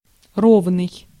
Ääntäminen
IPA: /y.ni.fɔʁm/